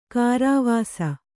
♪ kārāvāsa